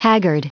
Prononciation du mot haggard en anglais (fichier audio)
Prononciation du mot : haggard